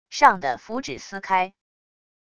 上的符纸撕开wav音频